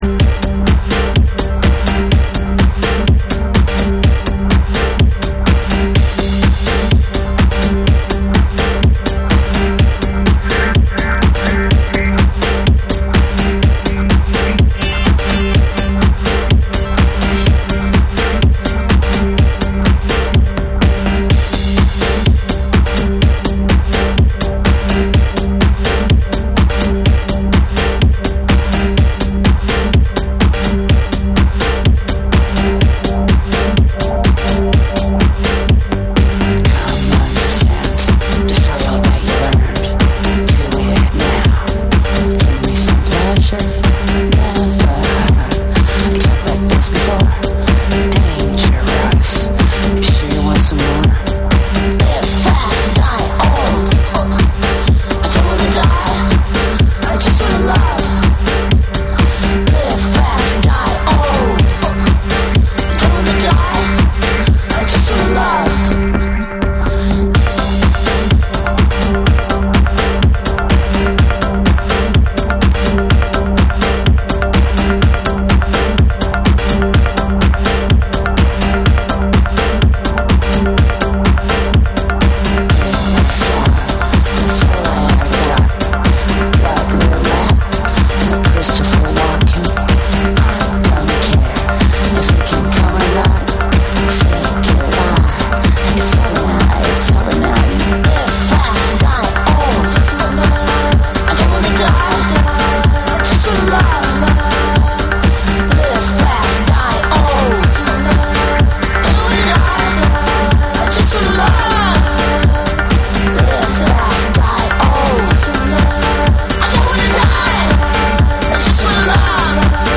NEW WAVE & ROCK / BREAKBEATS